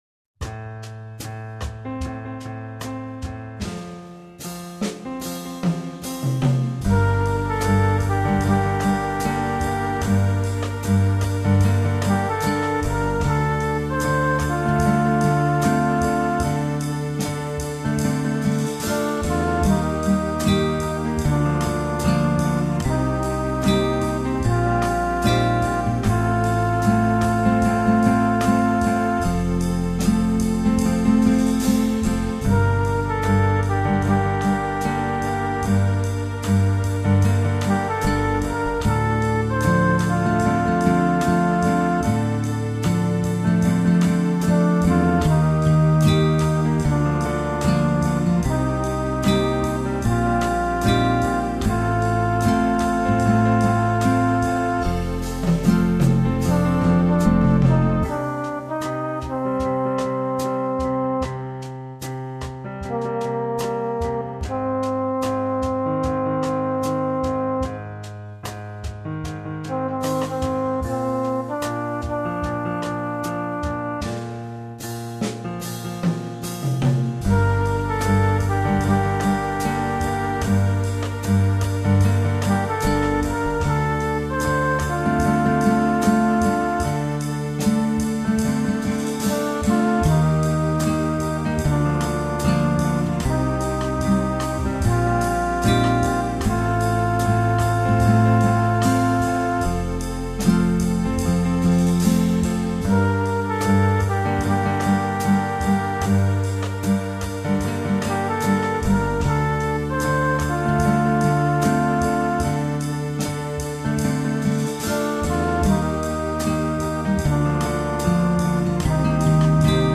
chant like P&W song